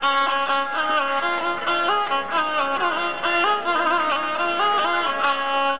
Ravanhatta.mp3